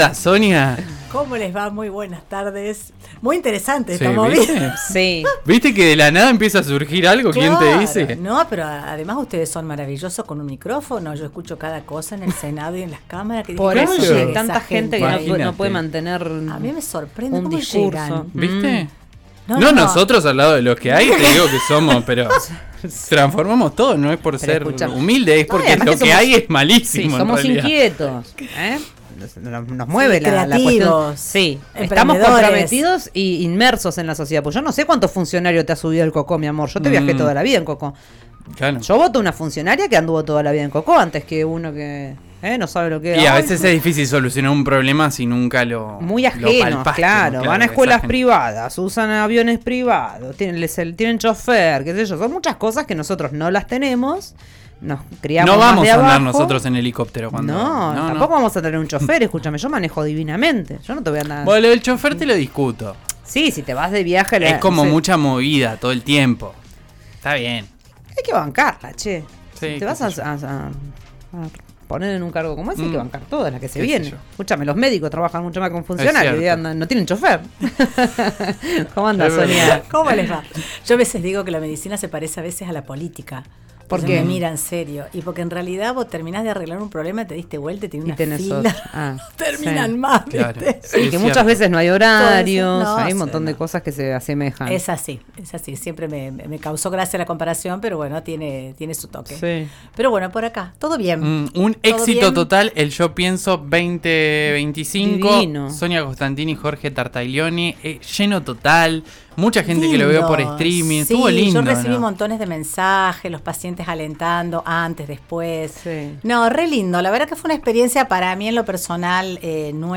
Escuchá la columna de salud en El diario del mediodía por RÍO NEGRO RADIO